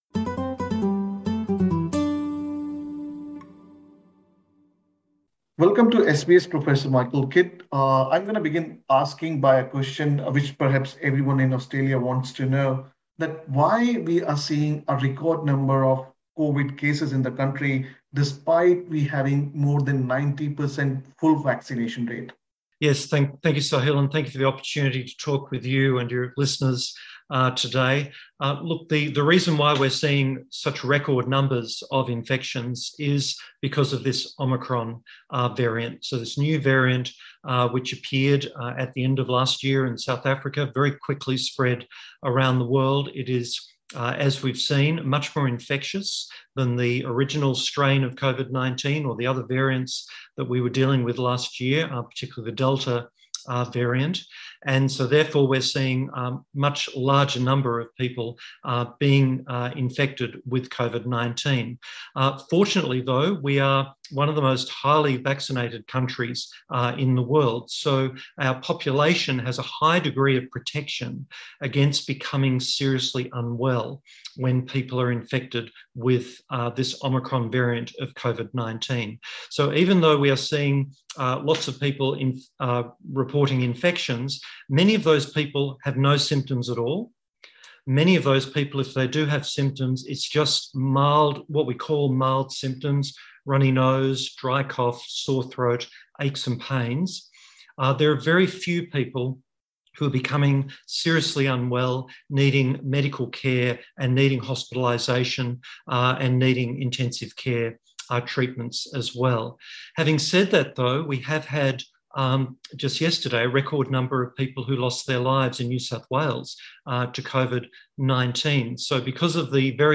Listen to Professor Michael Kidd's full interview by clicking the audio icon in the picture.